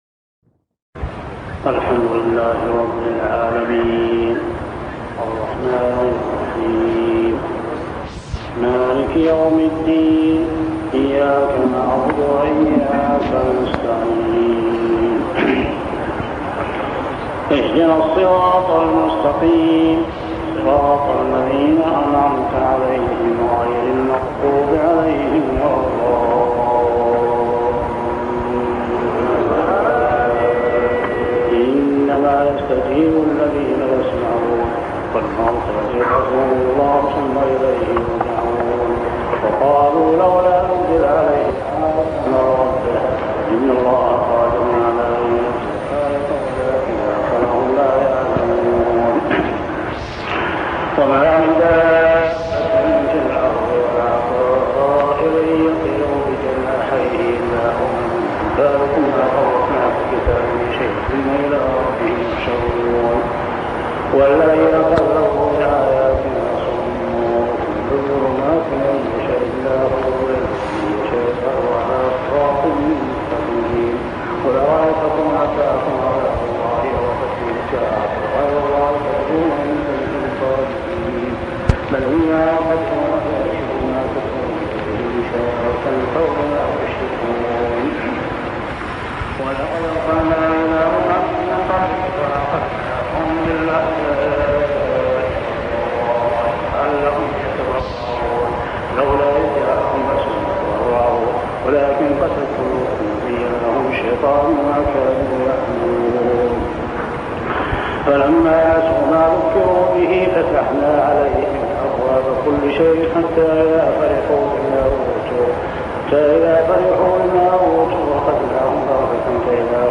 صلاة التراويح عام 1401هـ سورة الأنعام 36-110 | Tarawih prayer Surah Al-An'am > تراويح الحرم المكي عام 1401 🕋 > التراويح - تلاوات الحرمين